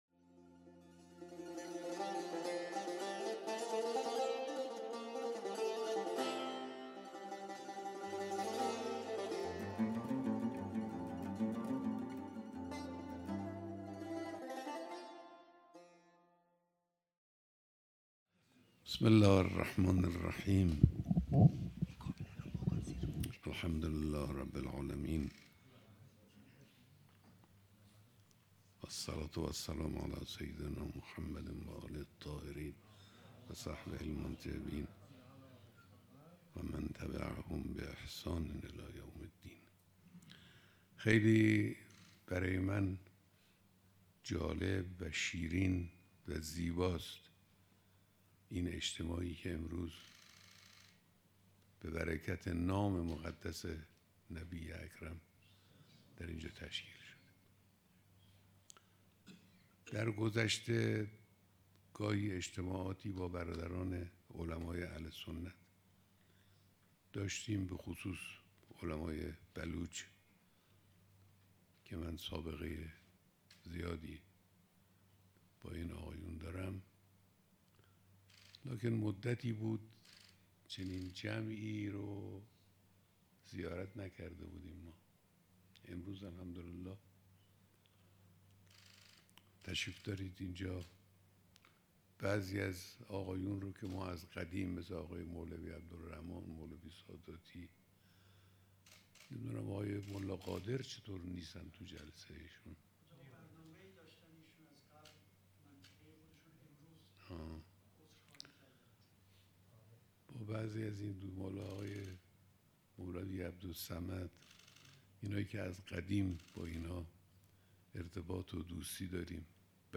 بیانات در دیدار جمعی از علما، امامان جمعه و مدیران مدارس علمیه اهل سنت سراسر کشور